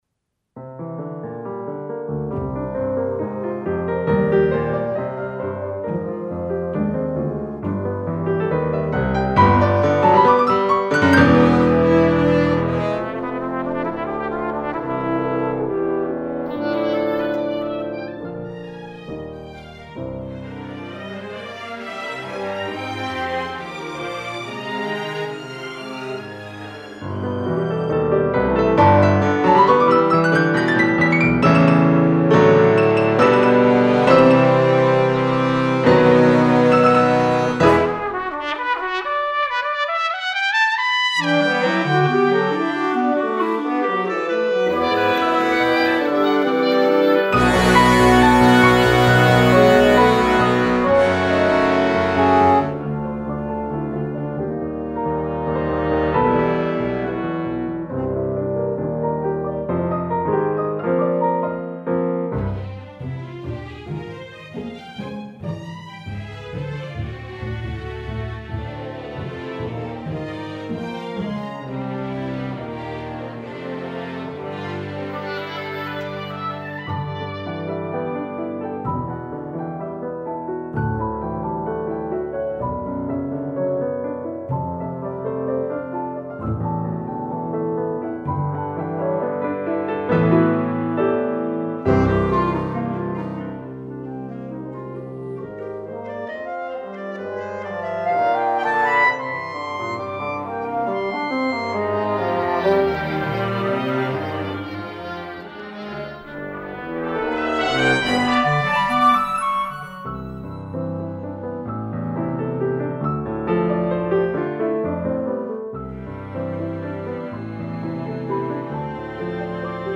Computer Realization